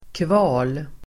Uttal: [kva:l]